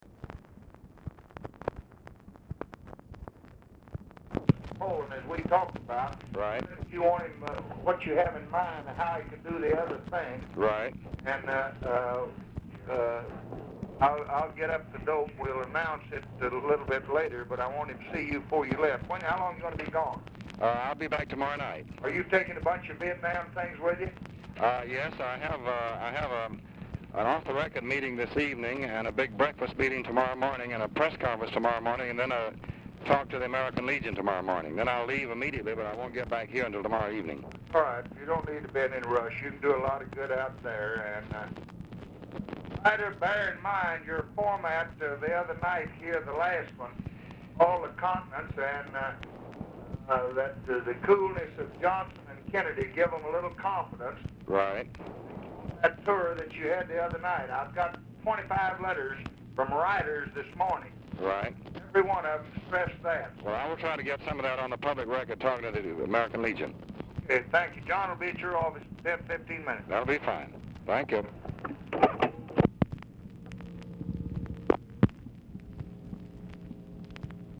Telephone conversation # 8609, sound recording, LBJ and DEAN RUSK
LBJ ON SPEAKERPHONE?; RECORDING STARTS AFTER CONVERSATION HAS BEGUN
Format Dictation belt